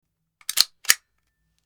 Довели патрон в патронник вручную